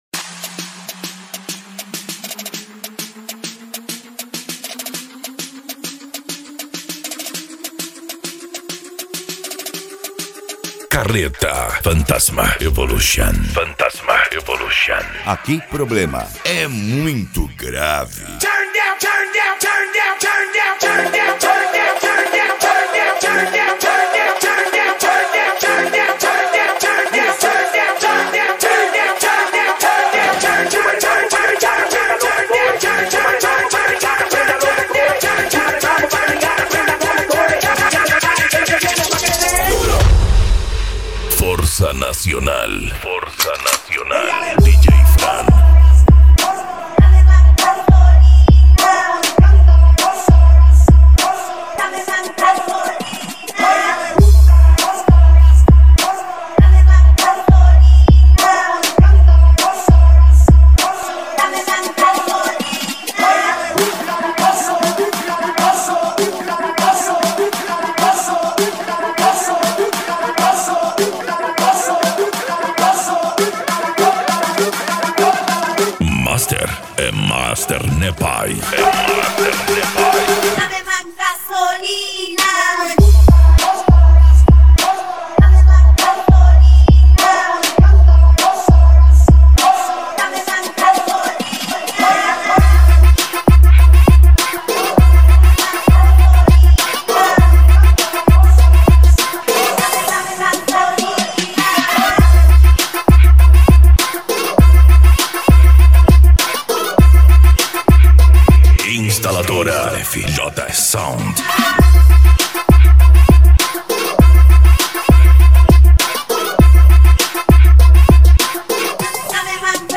Bass
Deep House
Eletronica
Mega Funk